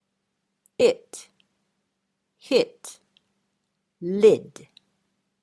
「イ」と「エ」の中間のような音。
it“＝「それ」 “hit“＝「打つ」 “lid“＝「ふた」